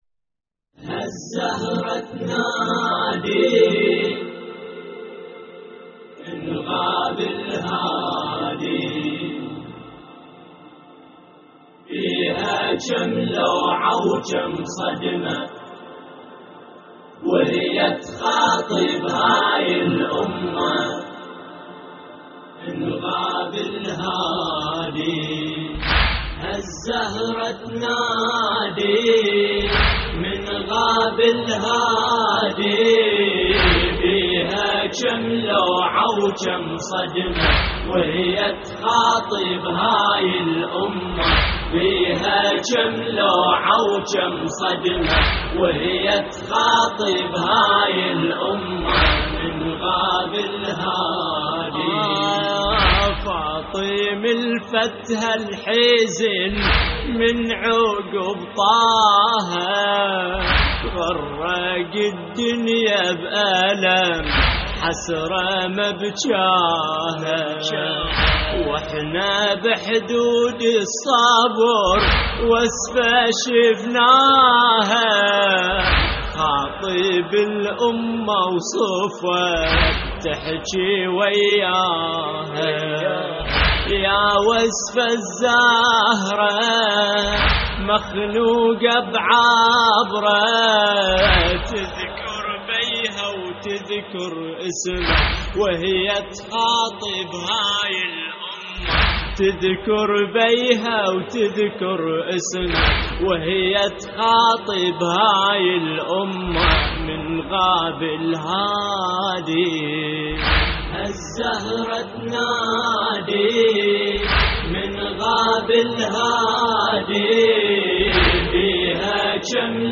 استديو
الرادود